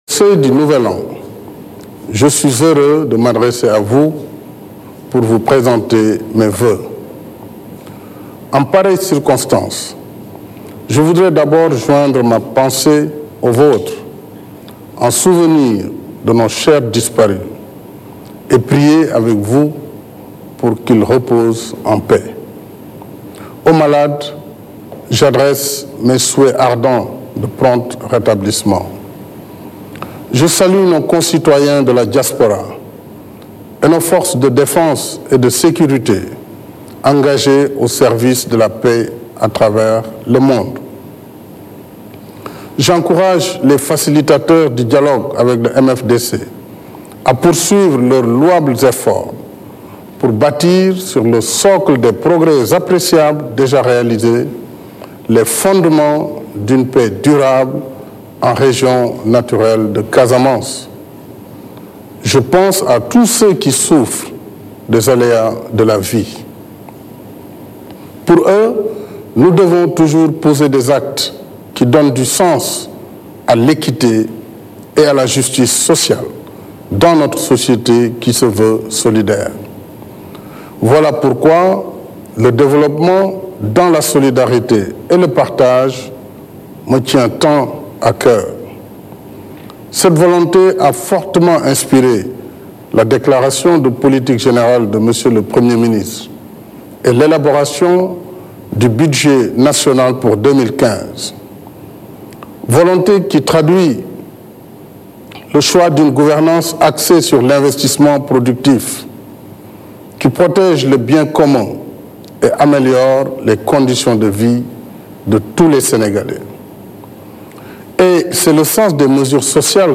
Message de nouvel an du chef de l'Etat - discours intégral: Macky Sall dresse un bilan et fixe les perspectives